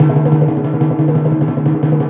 TIMP ROLL.wav